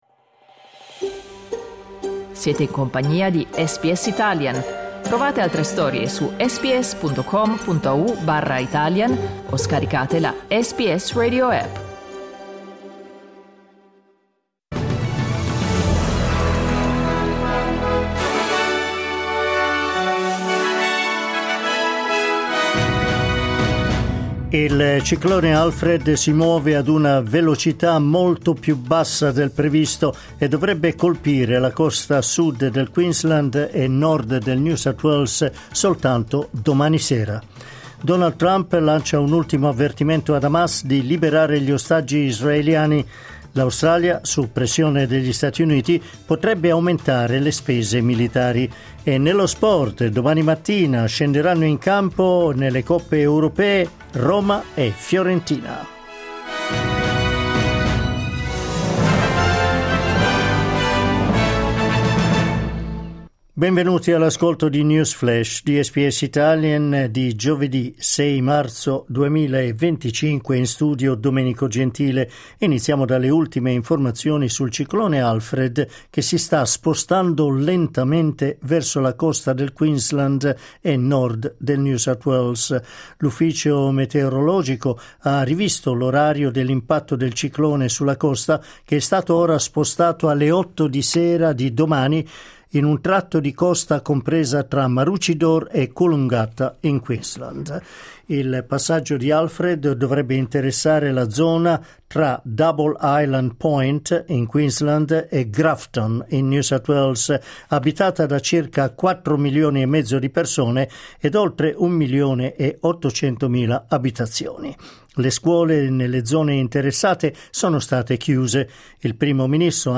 News flash giovedì 6 marzo 2025